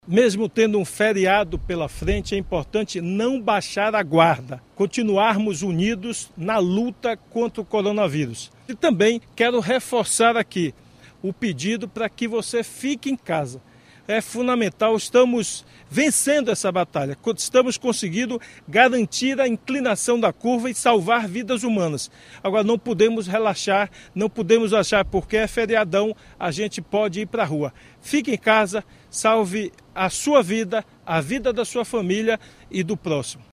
Estado suspende transporte intermunicipal em mais quatro cidades; governador deseja Feliz Páscoa - Report News Bahia